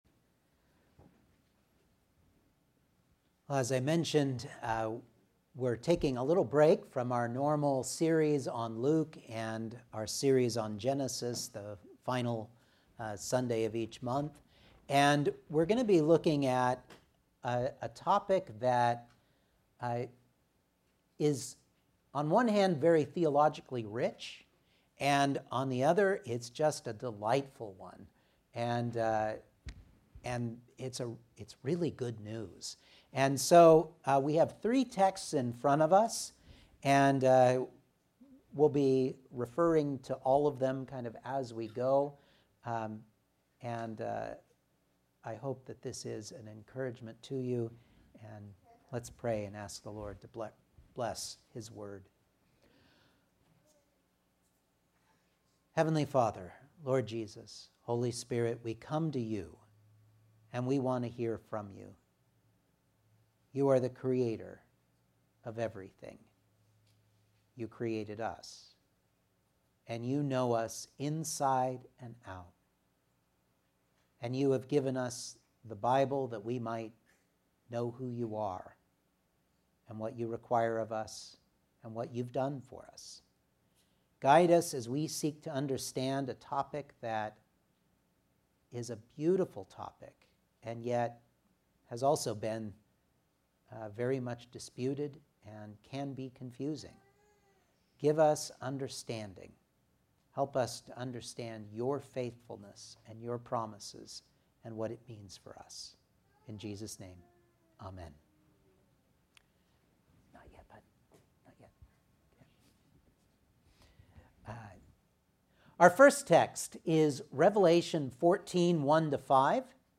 Revelation 14:1-5 Service Type: Sunday Morning Outline